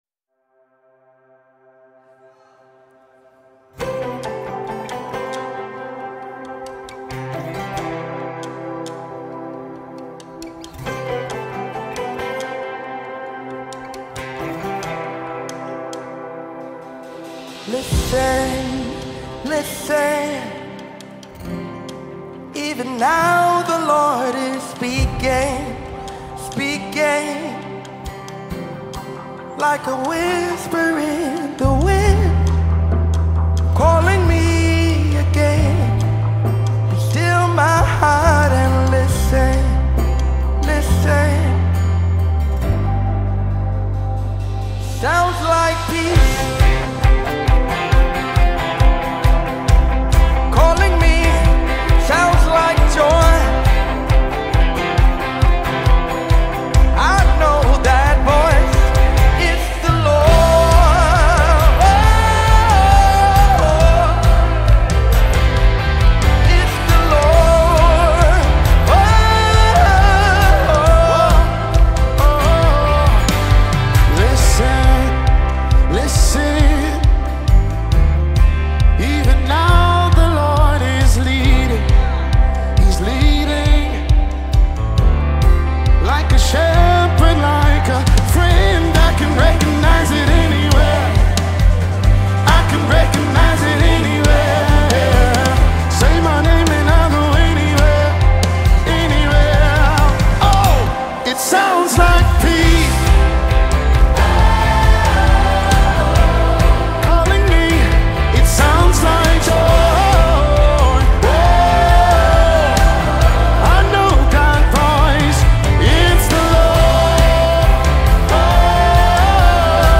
Top Christian Songs